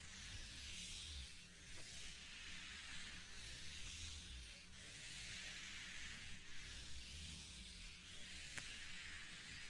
描述：通过电容式麦克风录制在隔音棚内。
Tag: 海浪 翻转 拖鞋